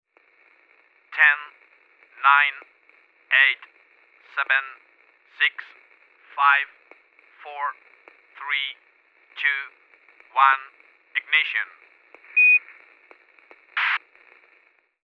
Cuenta atrás del lanzamiento de un transbordador espacial
Sonidos: Comunicaciones
Sonidos: Voz humana
Sonidos: Transportes